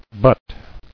[but]